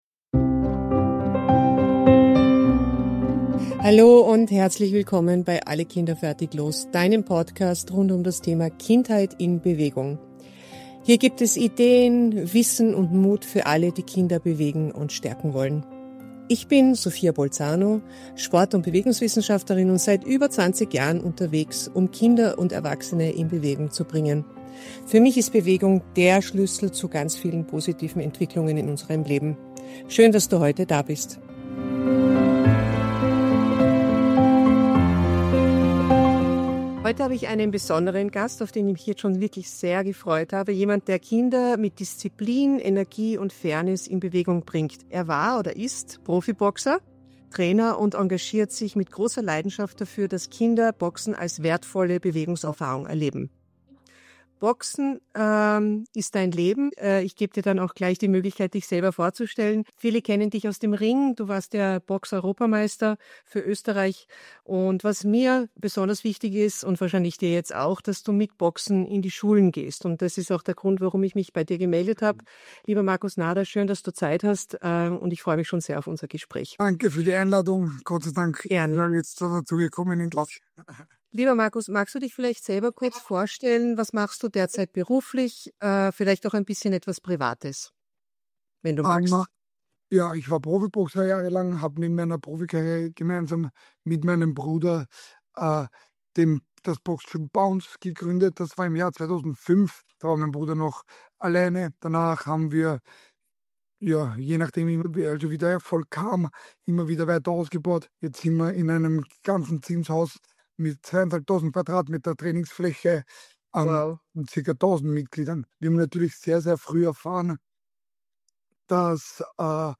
Ein Gespräch über Integration durch Bewegung, zweite Chancen für Jugendliche und die Verantwortung, die Erwachsene übernehmen können – und sollten.